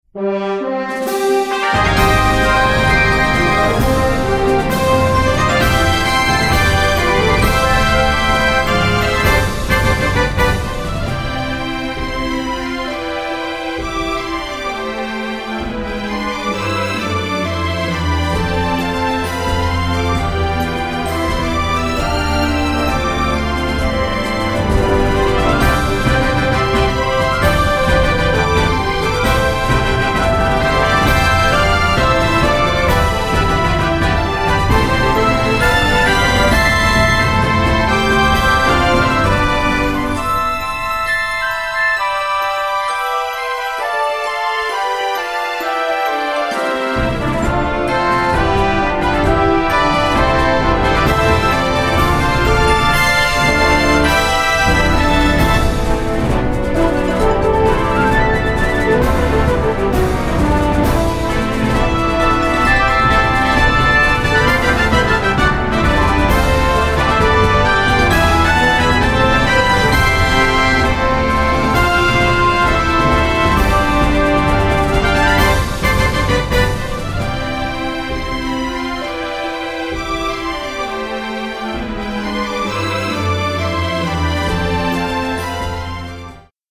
希望・行進・タイトル